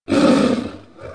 猛兽-动物声音-图秀网